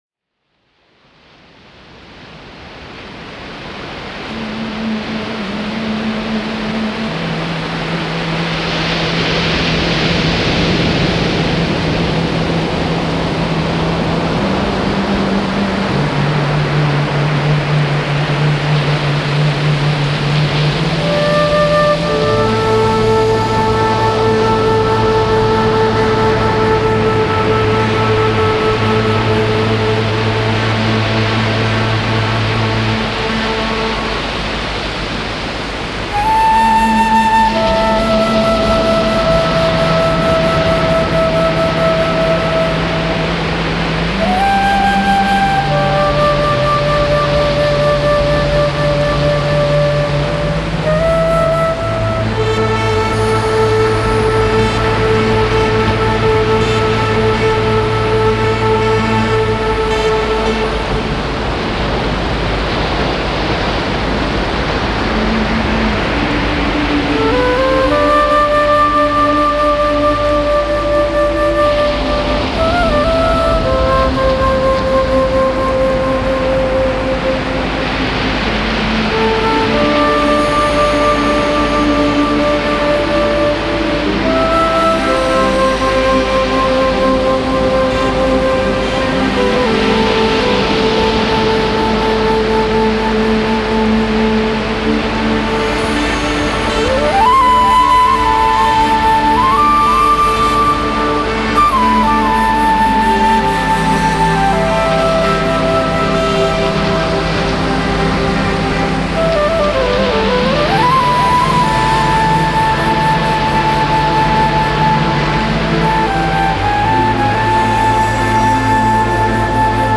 flute improvisations